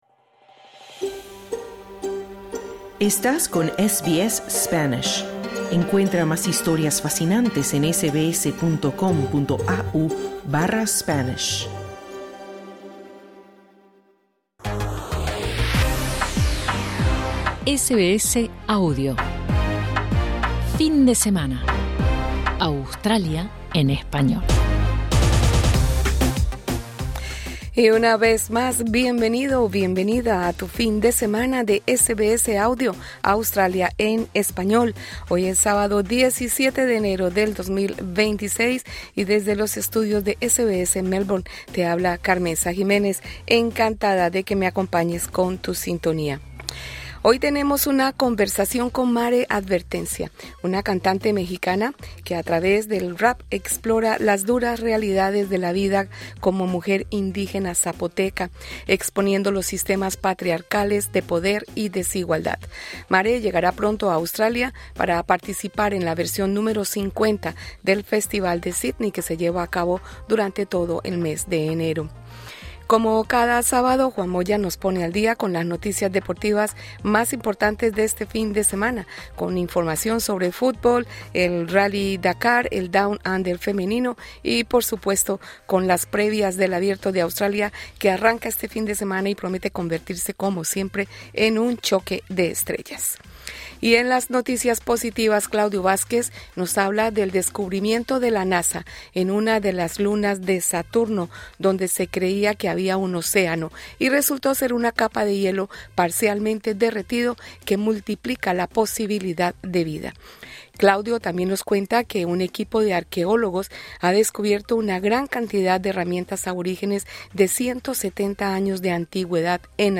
Programa de radio enero 17/2026